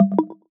Game Notification 80.wav